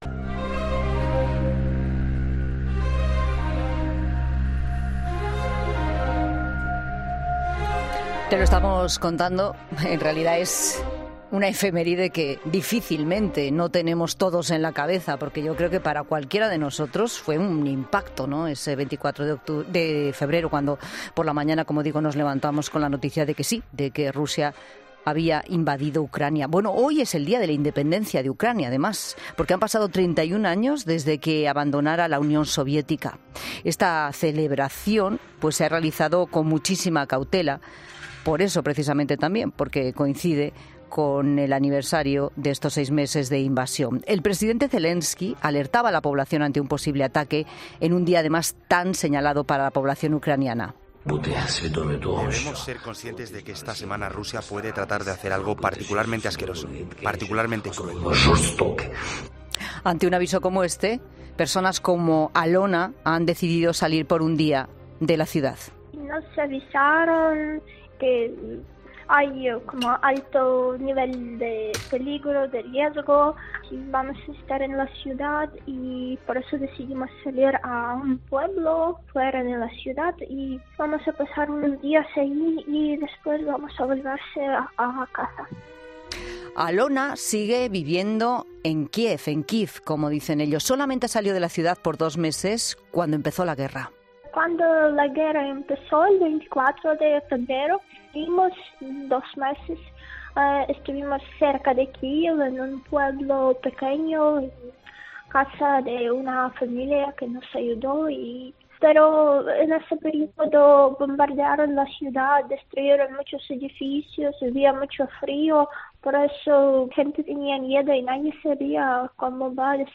Varios ucranianos han explicado a 'La Tarde' cómo han sido sus últimos seis meses, desde que Rusia decidiera invadir su país